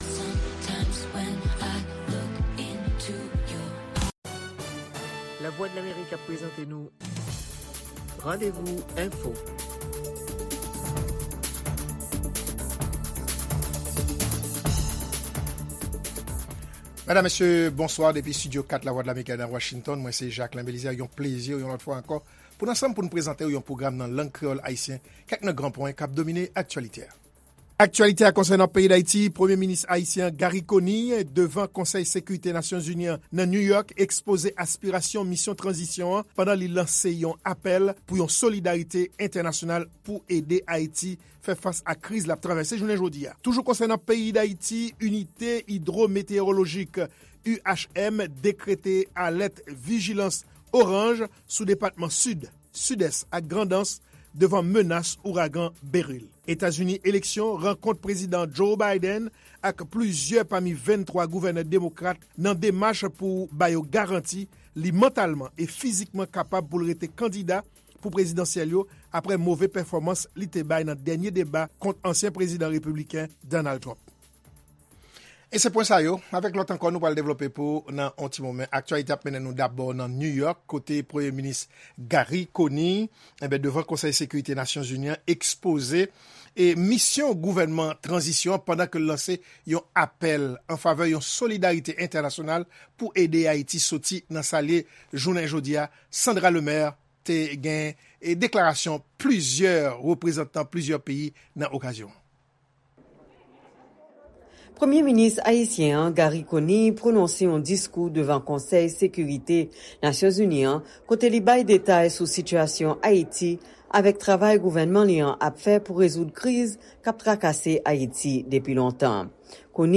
Randevou: Diskou PM Conille nan LONU